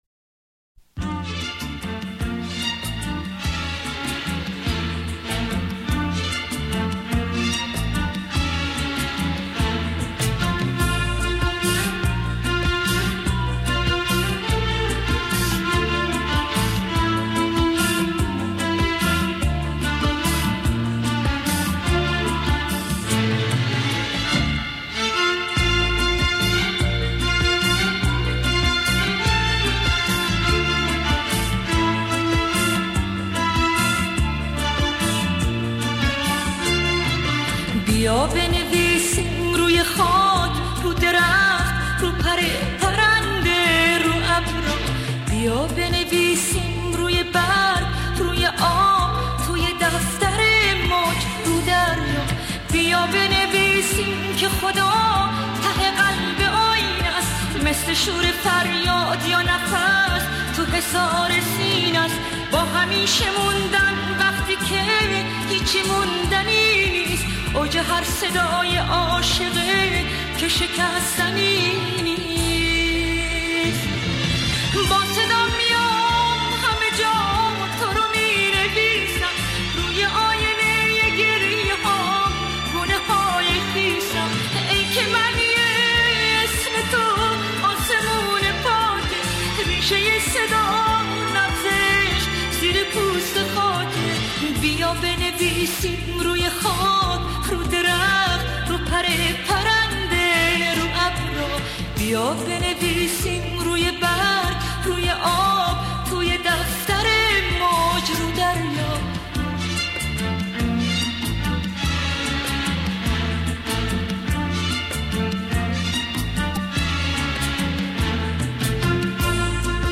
ژانر: پاپ
توضیحات: ریمیکس شاد ترانه های قدیمی و خاطره انگیز